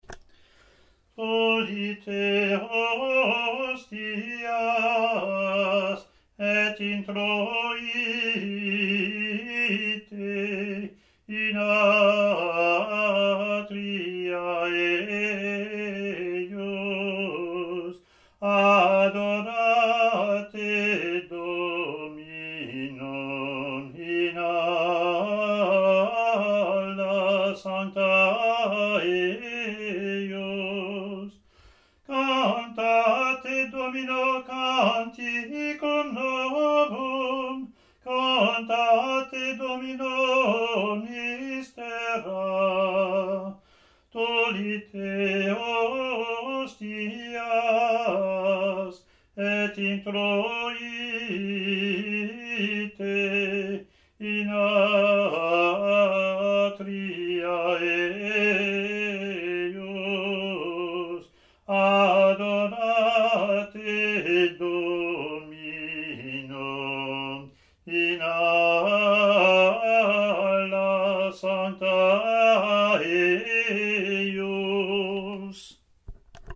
Year A Latin antiphon and verse, Year B Latin antiphon and verse,
ot24-comm-gm.mp3